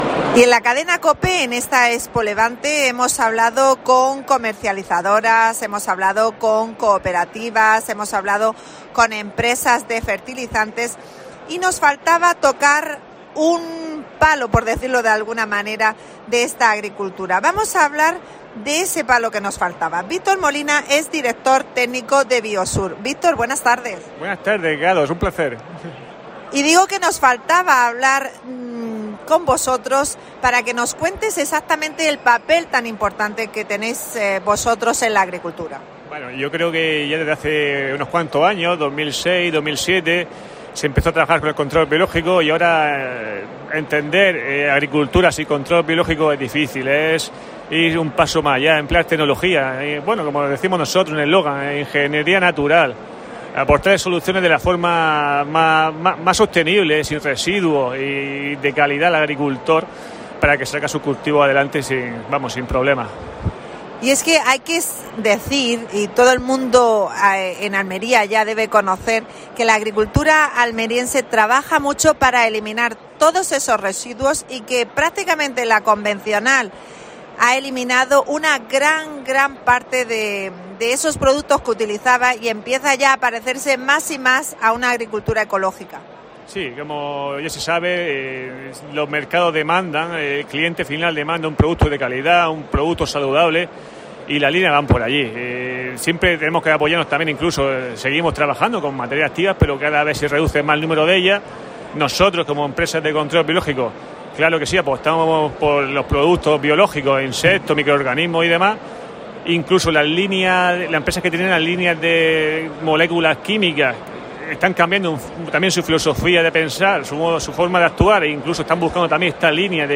Especial ExpoLevante: Entrevista